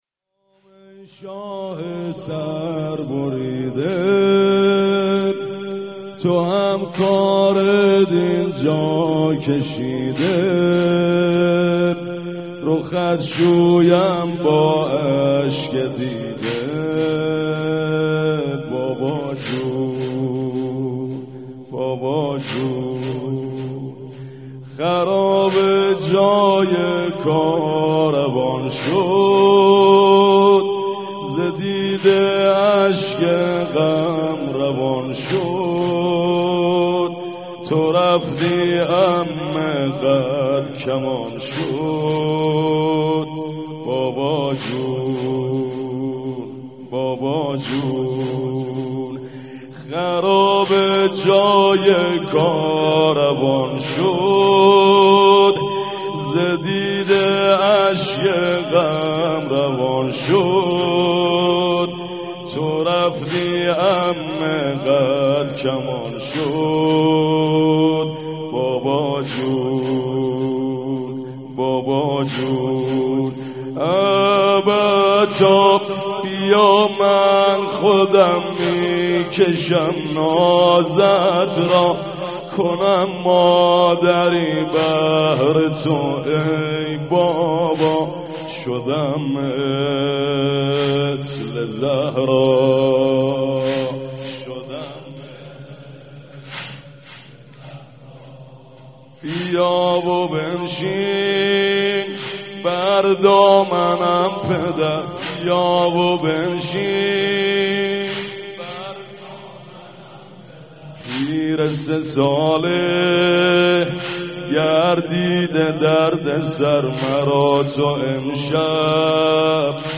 صوت مراسم شب سوم محرم ۱۴۳۷هیئت ریحانه الحسین(ع) ذیلاً می‌آید: